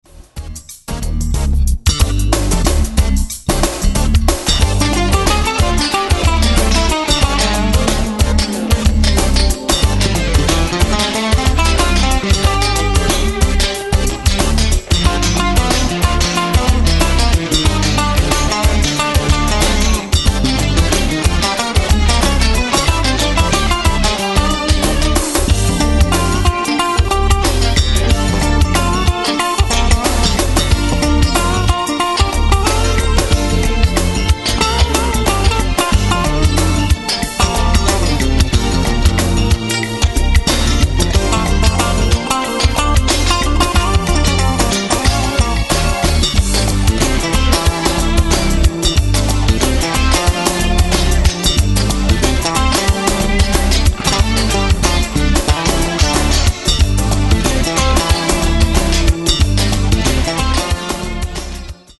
bass, keyboards
guitar